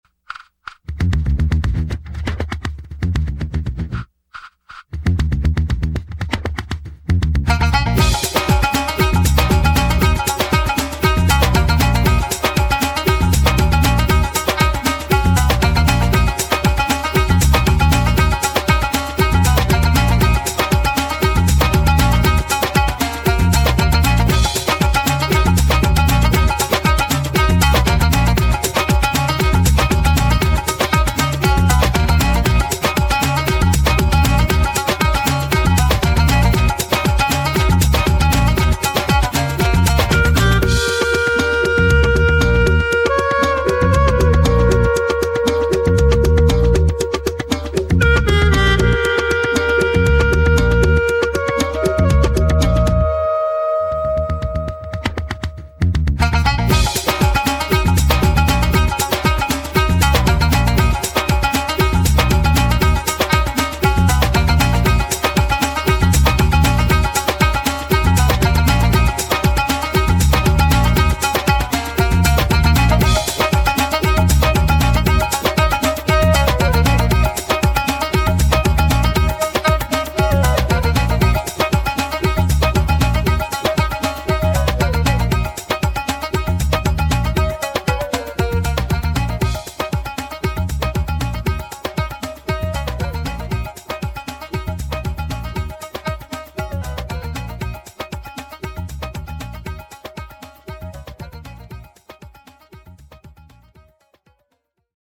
Dark Ambient / Tragic Cinematic / Post-Classical